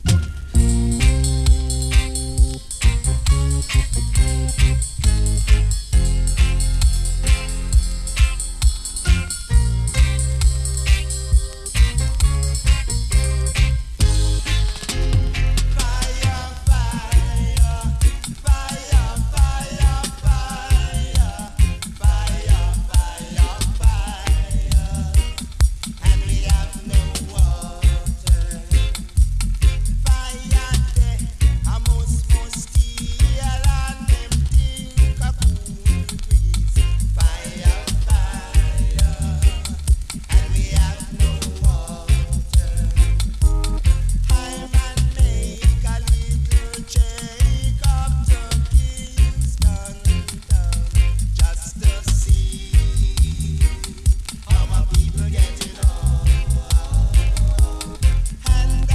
big roots